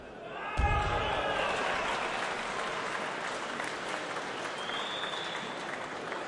描述：体育摔跤欢呼掌声体育场
Tag: 体育场 掌声 摔跤 体育 欢呼声